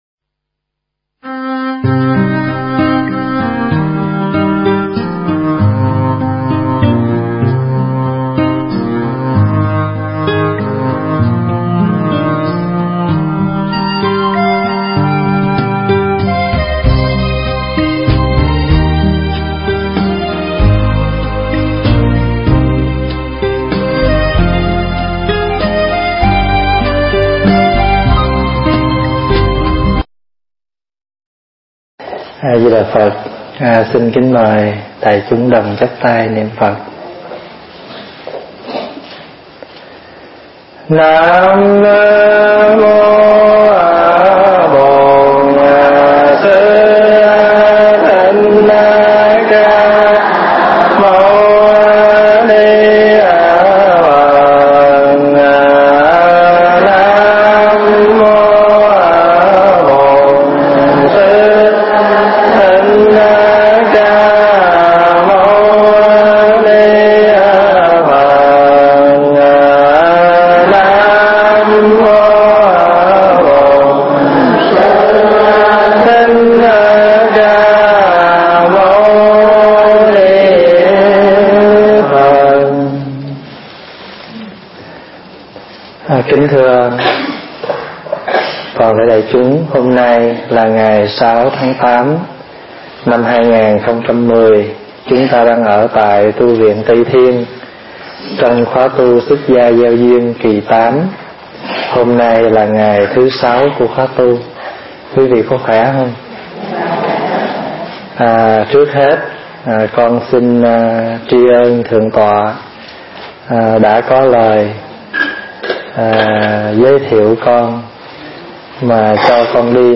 thuyết giảng tại Tu Viện Tây Thiên, Westlock County, AB, Canada